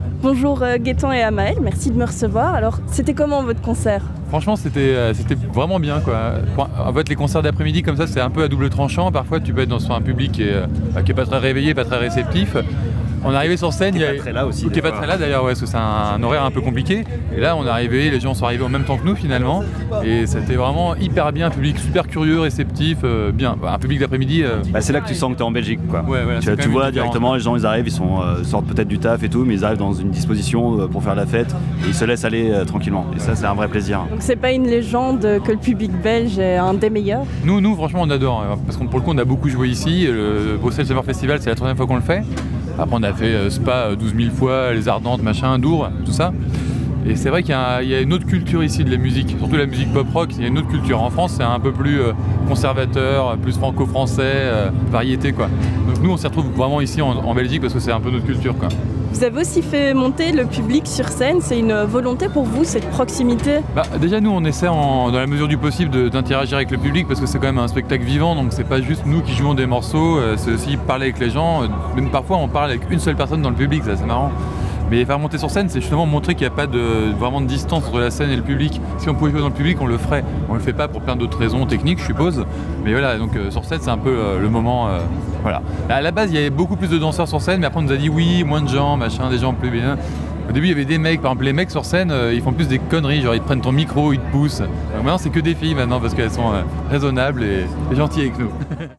nous ont reçus après leur concert pour nous livrer leurs impressions.